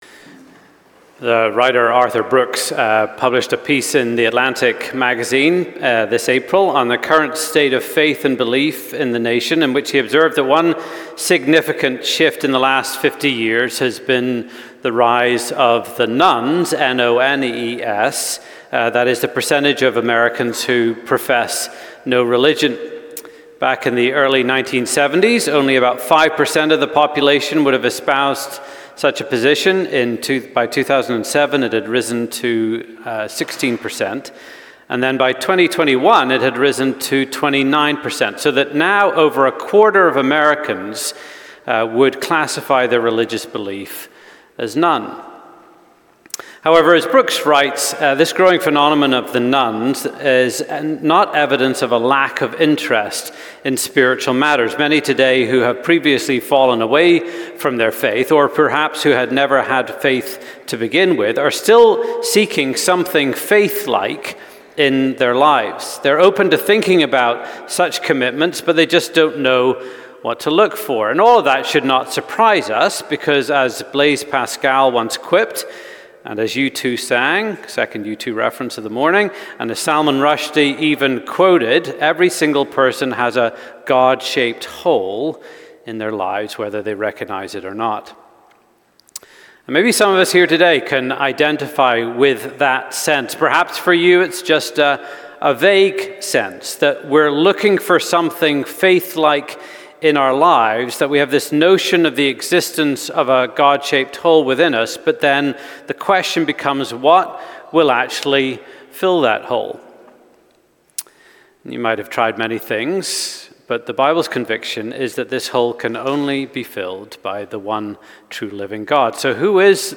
01_The-Living-God_Sermon.mp3